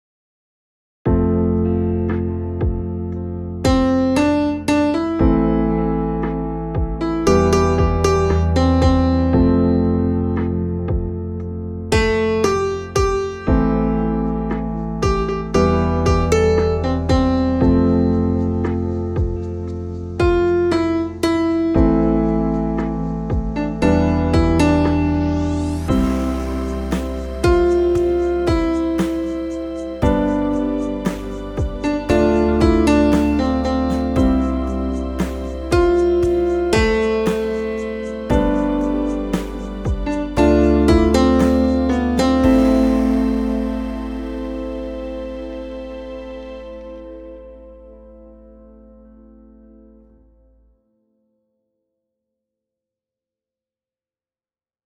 Guide for alto and soprano